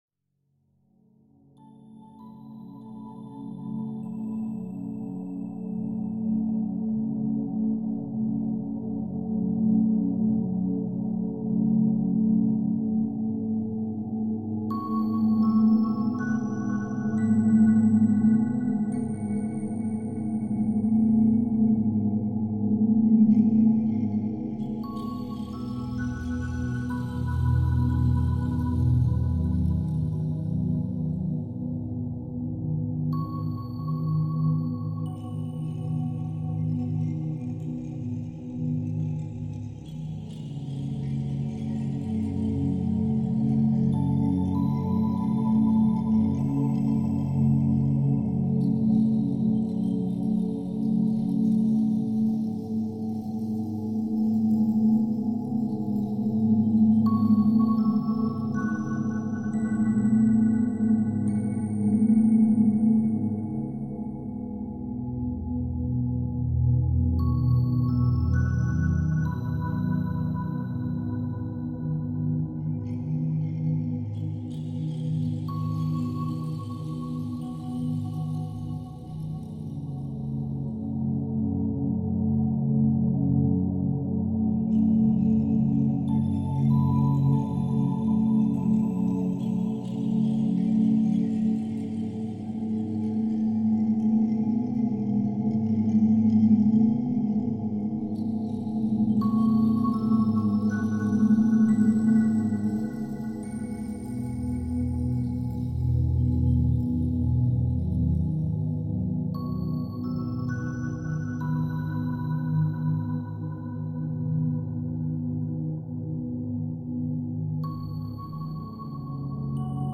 Расслабляющая музыка для спа без слов